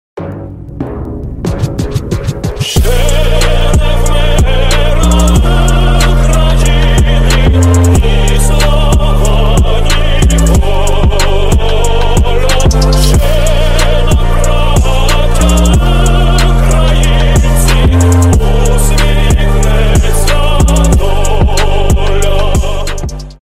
Громкие Рингтоны С Басами
Рингтоны Ремиксы